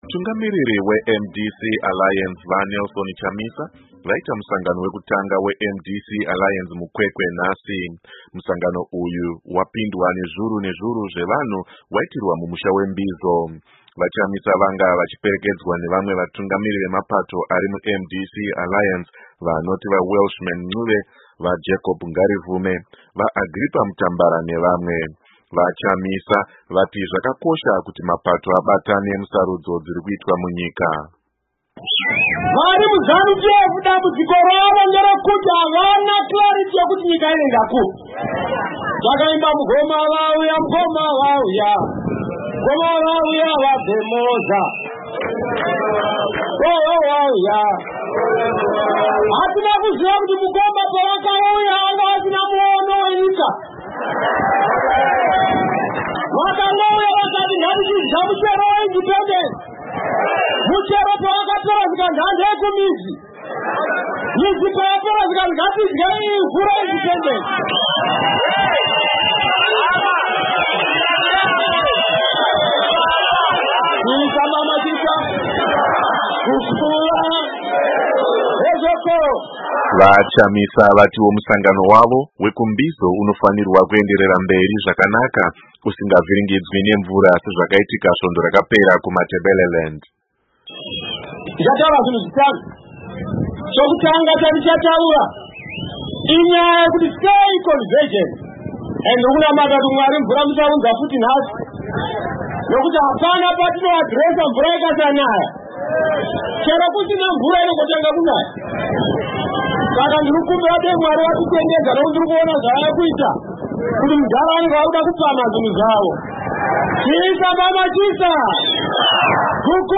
Musangano uyu, uyo wapindwa nezviuru nezviuru zvevanhu, waitirwa mumusha we Mbizo.
VaChamisa Vachitaura kuMbizo muKwekwe